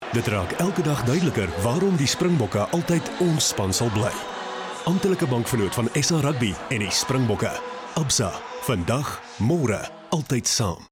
South African, SA English, Afrikaans, smooth, clear, crisp, retail, character, professional voice over, corporate, hard sell, soft sell, in house, gaming, documentary, conversational
Sprechprobe: Industrie (Muttersprache):